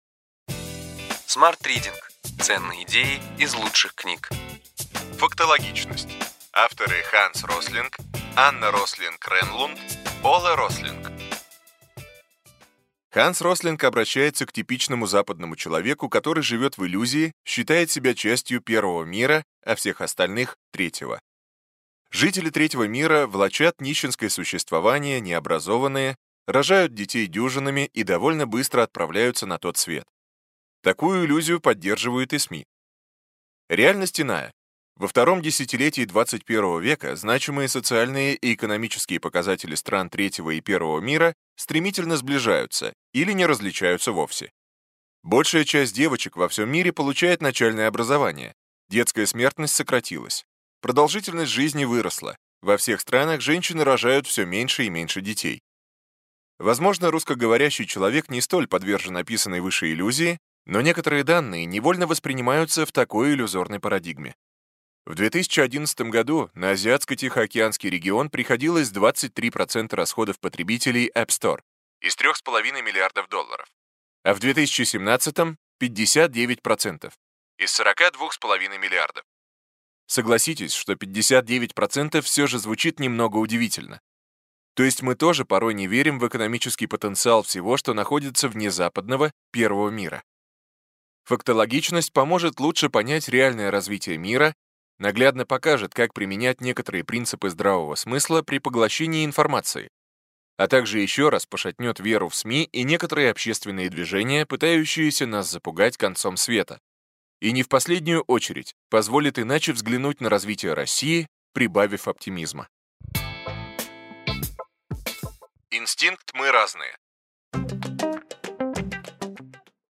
Аудиокнига Ключевые идеи книги: Фактологичность: десять причин, почему наши суждения о мире ошибочны и почему дела лучше, чем мы думаем.